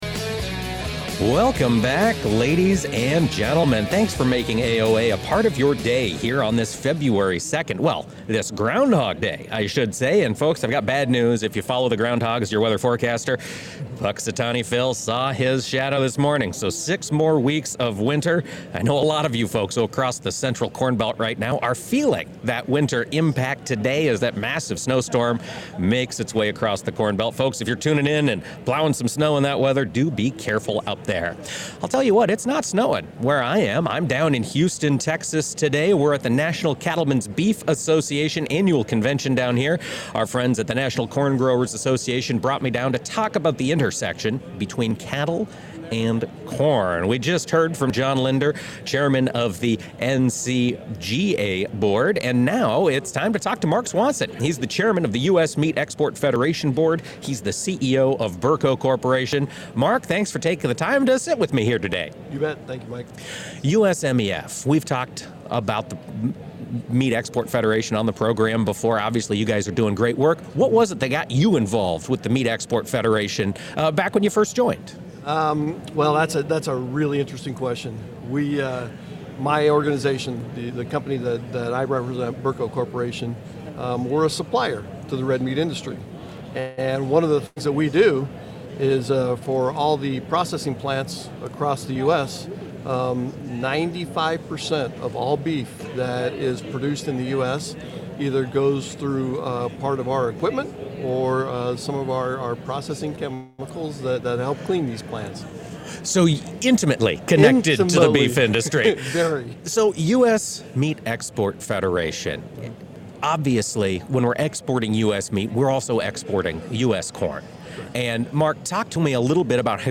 Record-large beef exports were a major focus for reporters covering the Cattle Industry Annual Convention, held Feb. 1-3 in Houston. On-site interviews with USMEF leadership include: